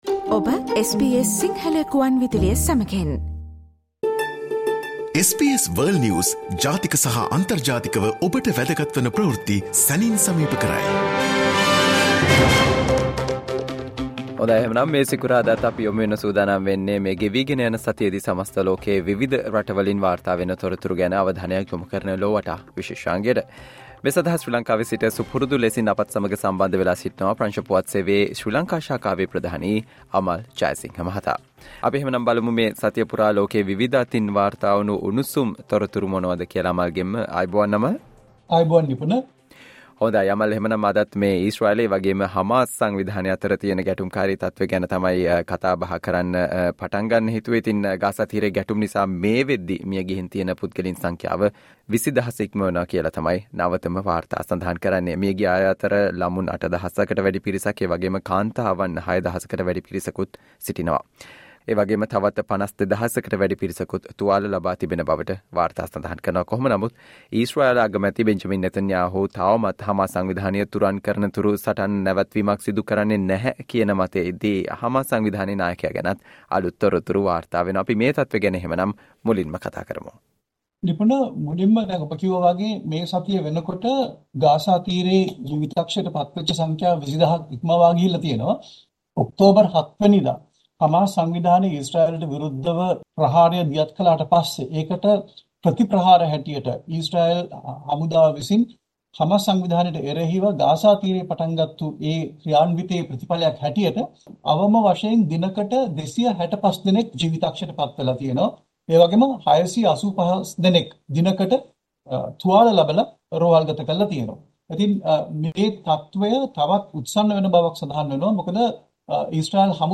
listen to the world’s most prominent news highlights.